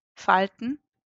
IPA['faltən] ?/i wymowa austriacka?/i